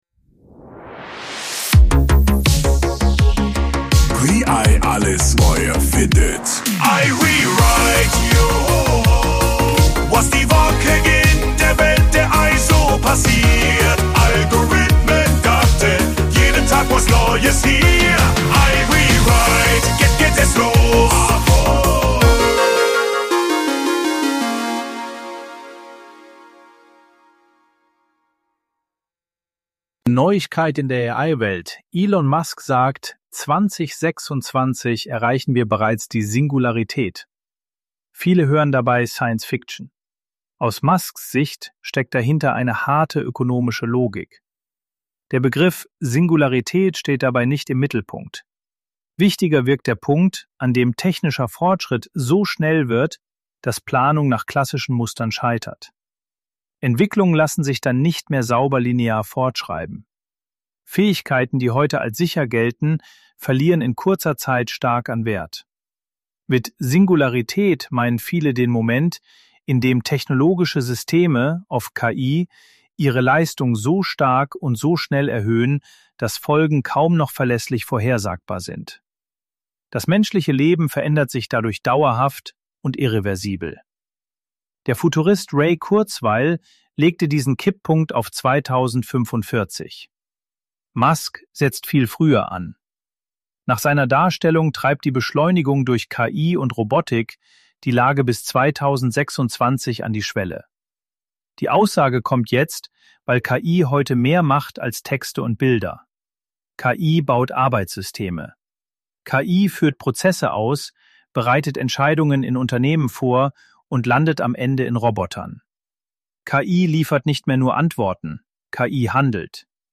Mal spreche ich , mal meine digitale Stimme.